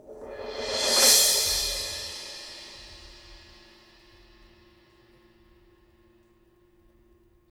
Index of /90_sSampleCDs/Roland LCDP03 Orchestral Perc/CYM_Cymbal FX/CYM_Mallet Rolls